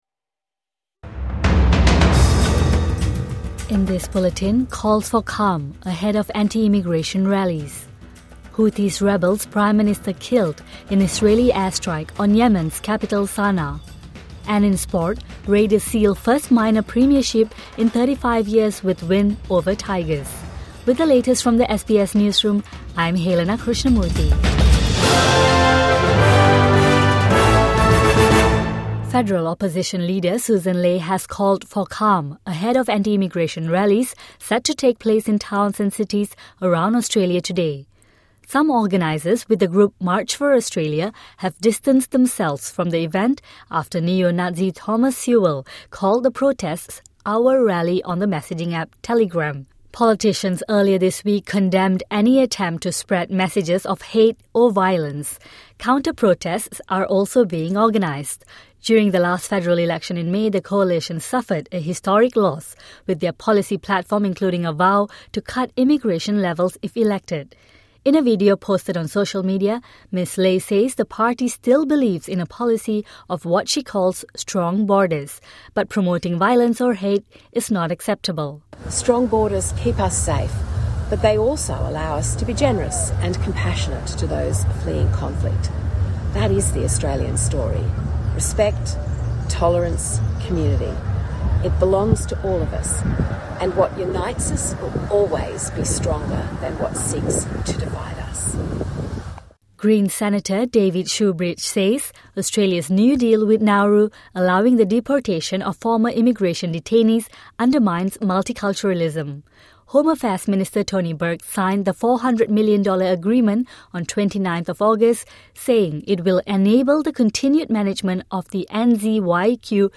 Calls for calm ahead of anti-immigration rallies | Morning News Bulletin 31 August 2025